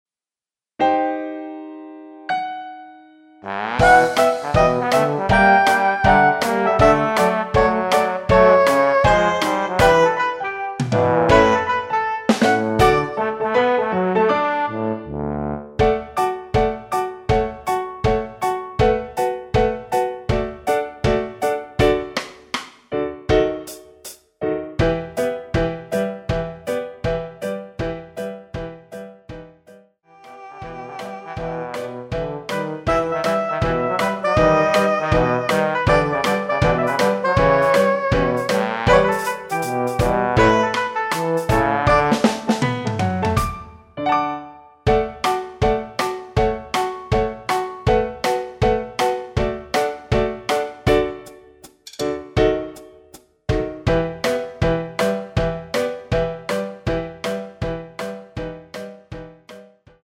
대부분의 남성분이 부르실수 있는 키로 제작 하였습니다.
앞부분30초, 뒷부분30초씩 편집해서 올려 드리고 있습니다.
중간에 음이 끈어지고 다시 나오는 이유는